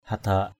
/ha-d̪a:ʔ/ (d.) bí đao = courge (Cucurbita pepo).